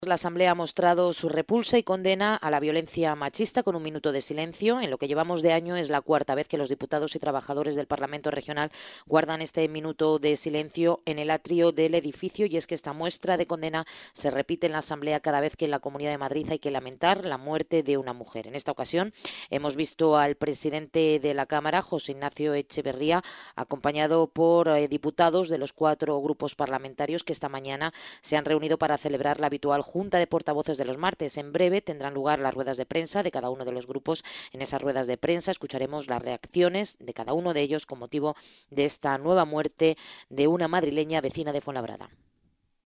Informativo mediodía
Como cada vez que hay una víctima por violencia machista, los miembros de la Mesa de la Asamblea, diputados y trabajadores de la Cámara se concentrarán a mediodía en el patio del edificio para expresar su condena por el nuevo asesinato.